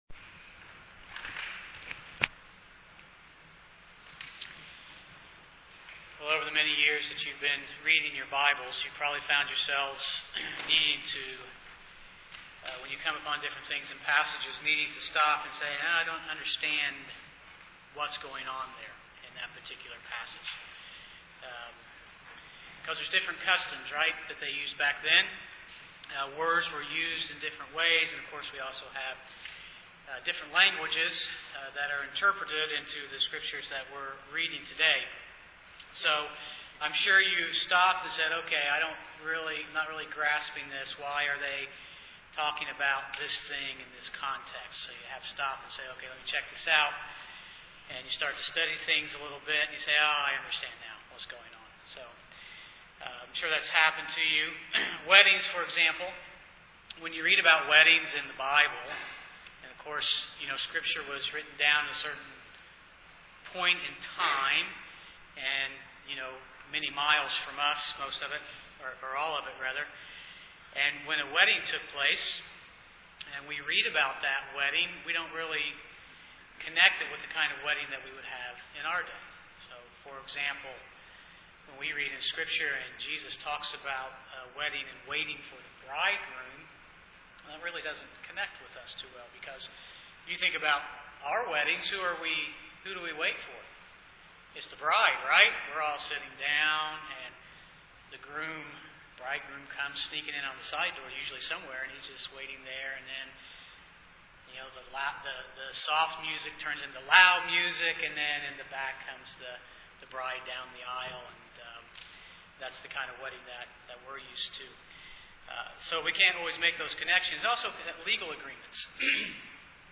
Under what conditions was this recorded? Psalm 90 Service Type: Sunday morning Bible Text